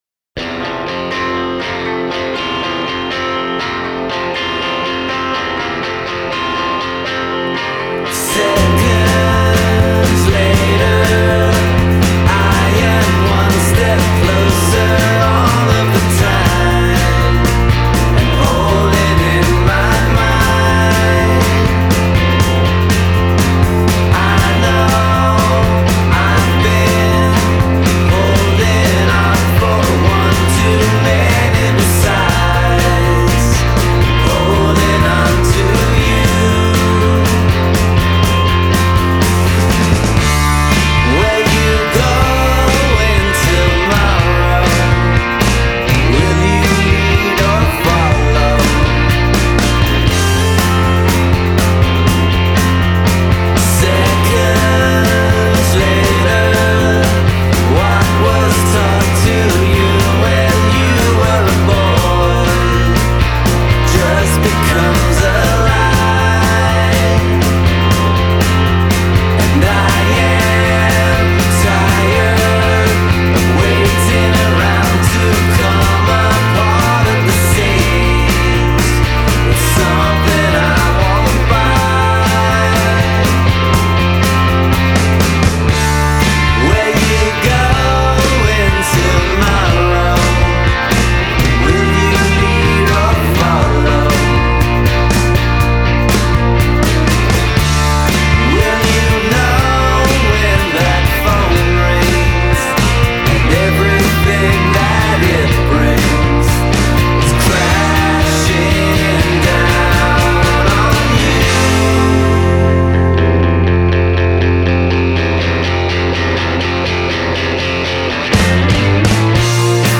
amazing 1960s-inspired single